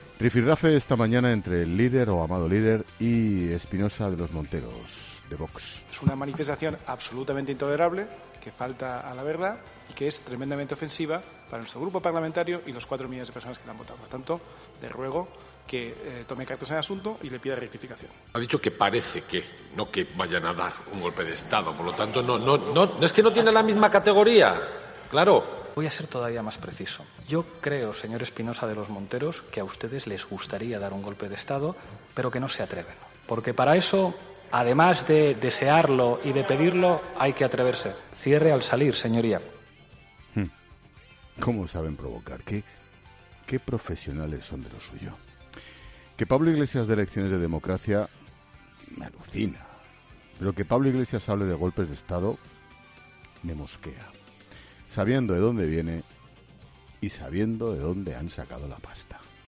El presentador de 'La Linterna' ha vuelto a ser muy crítico con el vicepresidente segundo del Gobierno
Después de escuchar todo esto, Expósito no ha podido evitar una pequeña carcajada ante el micrófono.